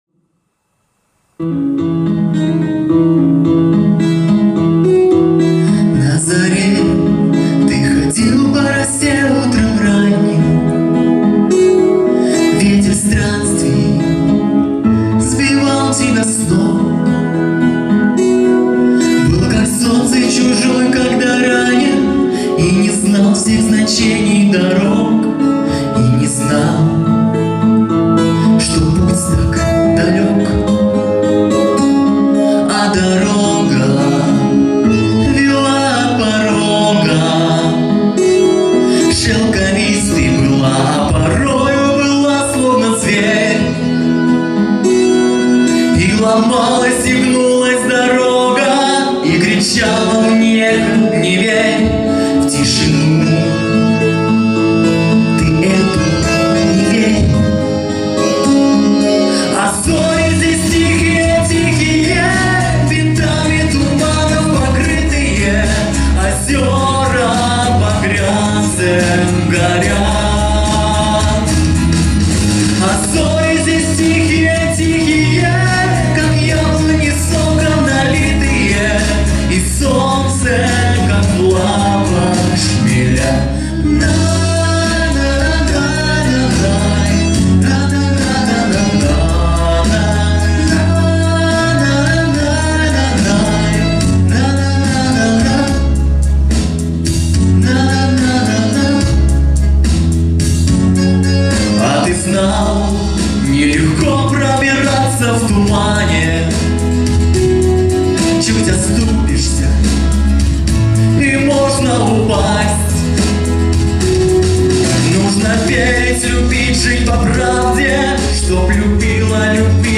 Прощу прощения я плохую запись.